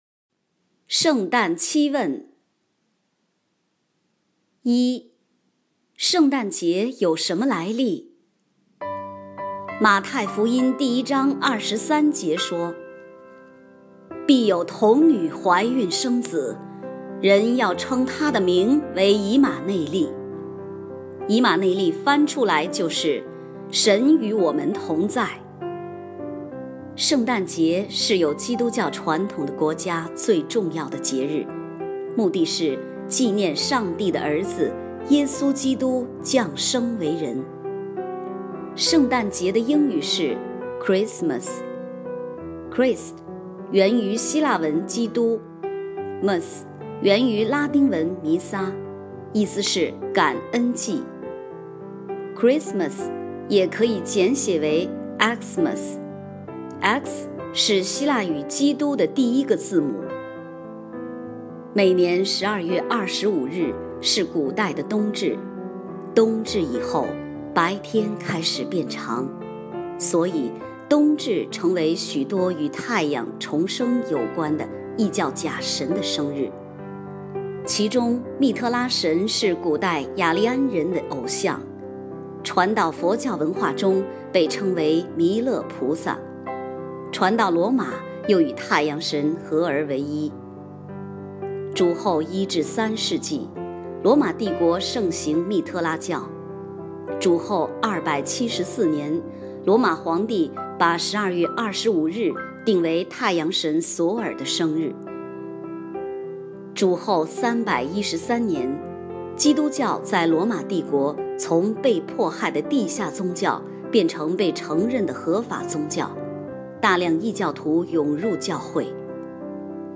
（配乐：圣善夜 O Holy Night）